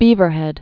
(bēvər-hĕd)